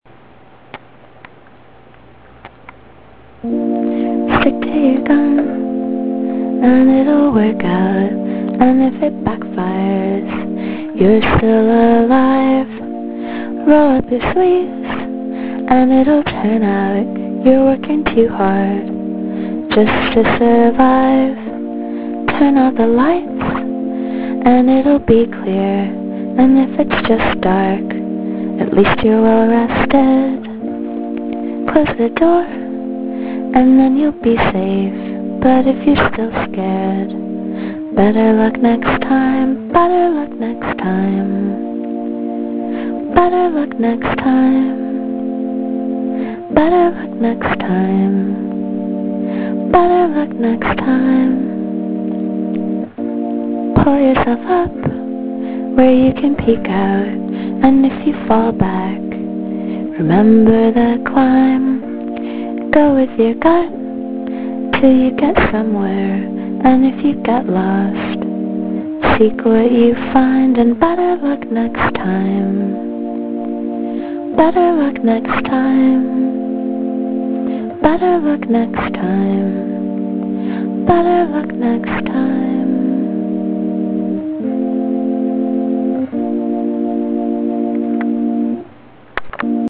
b major, possibly.
a-a-a-a-b-a-a-b
this song was recorded using my camcorder's internal mic and wav recording capability. this song uses a lot of cliches and is basically the same chords as the passerines song "young." nonetheless it seems kind of effective. i will probably re-record it.
"i really like the five chord verse, it will lend itself to cool drum beats. and keeps the listener on their toes without really knowing why. i also like the abruptness of the refrain, and the way the pace of the changes slows down for it. it seems to me that you could improve the momentum of the chorus if, rather than simply holding out that second chord all eight beats, you put in a diminished chord for the last two beats, and then the second half of the chorus will feel like it's really doing something important.